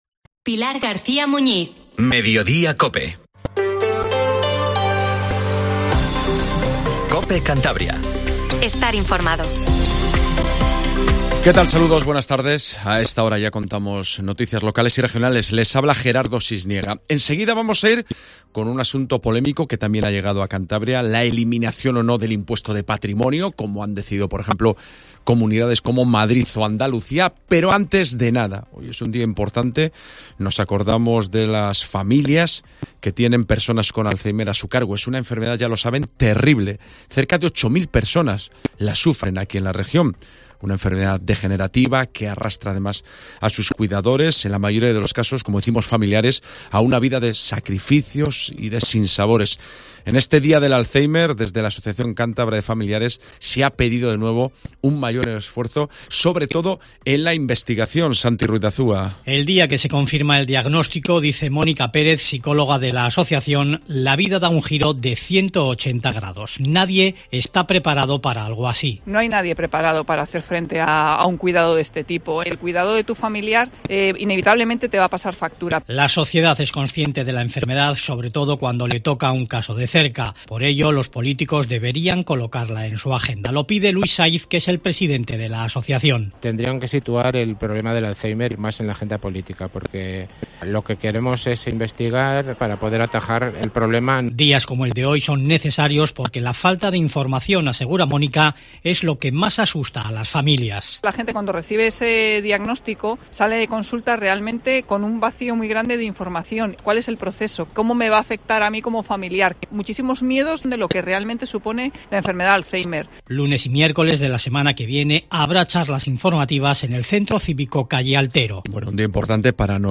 Informtivo Mediodía COPE CANTABRIA